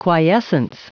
Prononciation du mot quiescence en anglais (fichier audio)
quiescence.wav